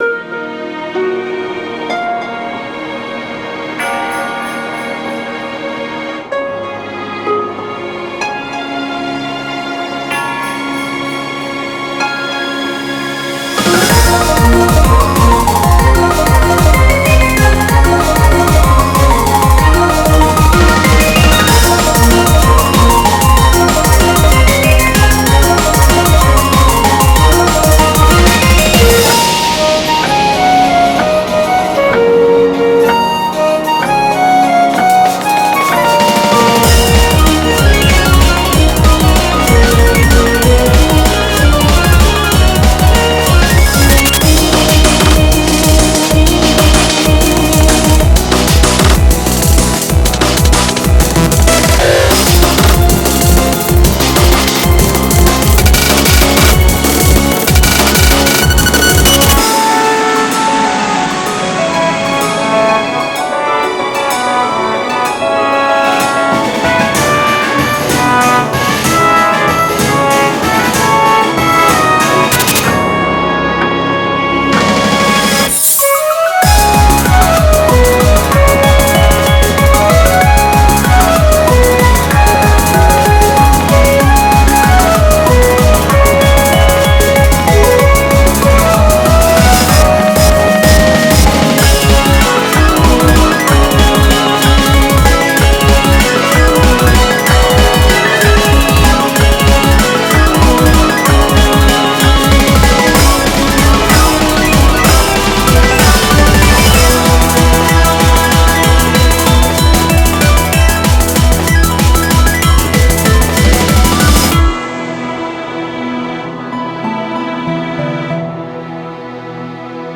BPM35-190
Audio QualityCut From Video